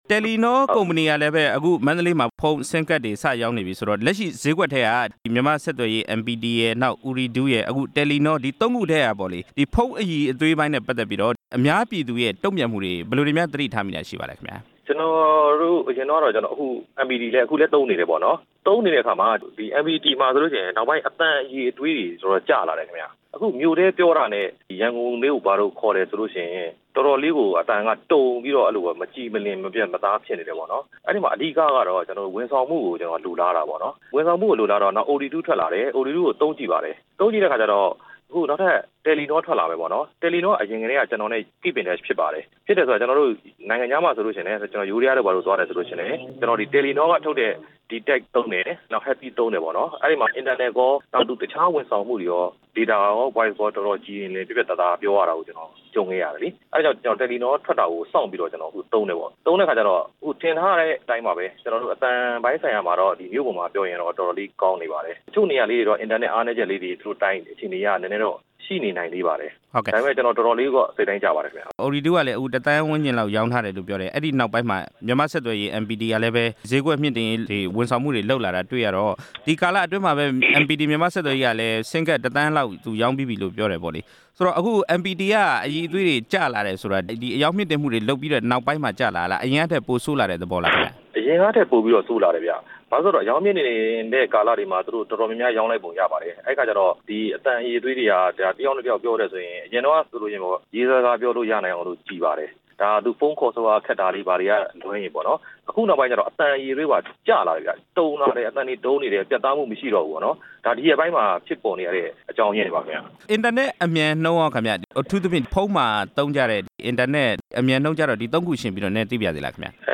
MPT၊ အော်ရီဒူးနဲ့ တယ်လီနော အကြောင်း မေးမြန်းချက်